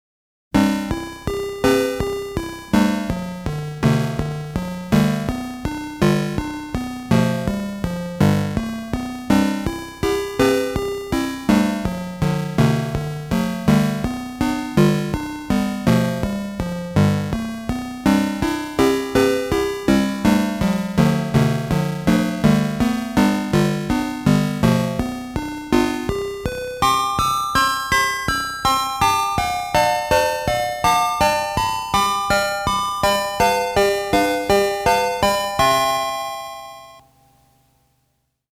Atari-ST Tunes